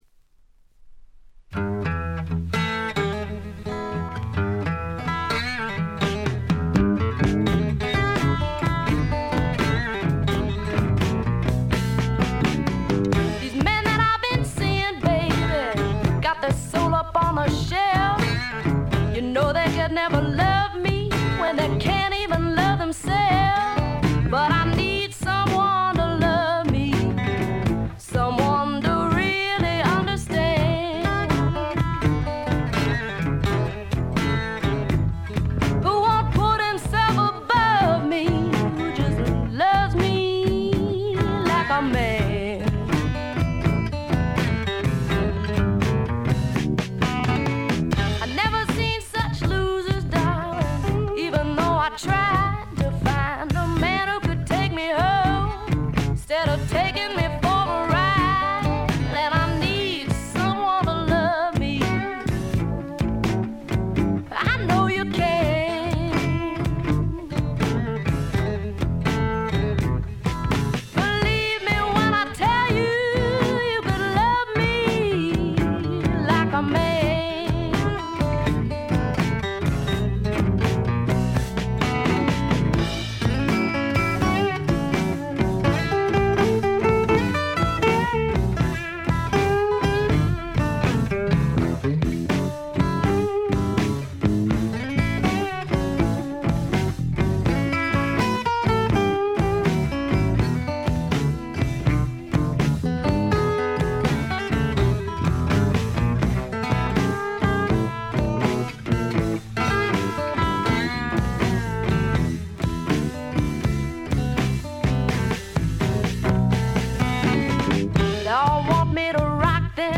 ウッドストック・べアズビル録音の名盤としても有名です。